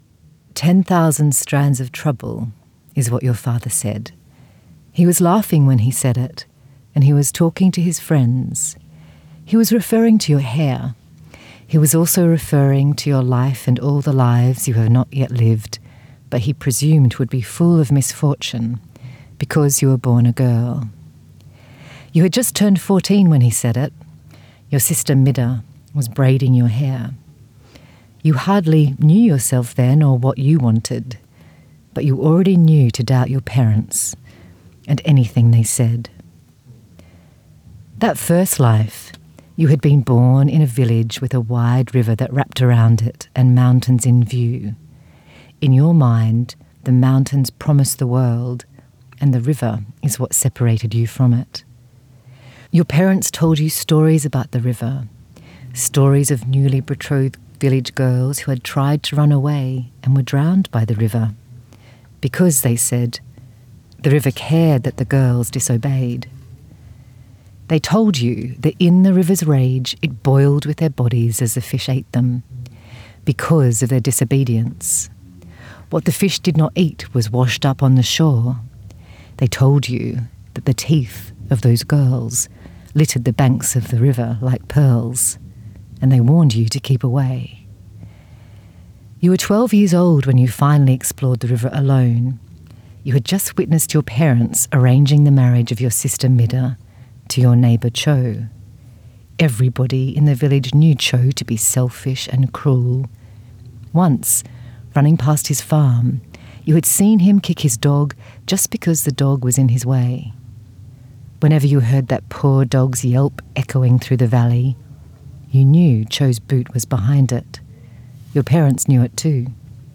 Recorded at Bellingen Readers and Writers Festival 2025